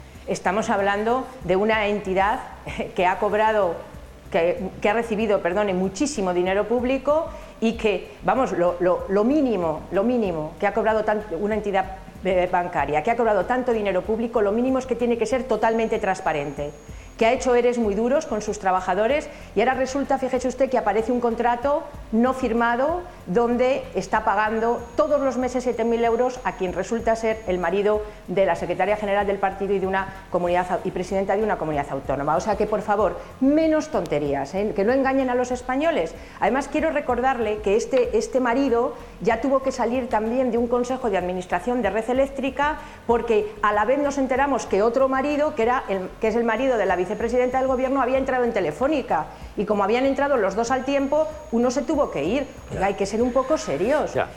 Fragmento de una entrevista en Las mañana de Cuatro el 30/01/2014.